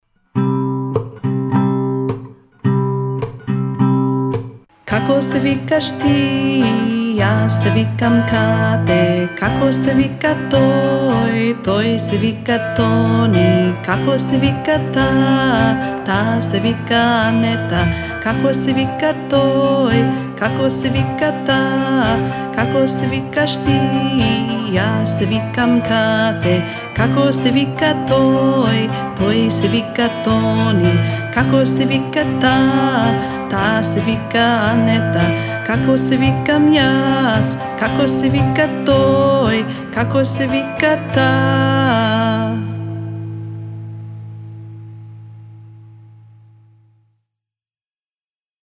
song_2.mp3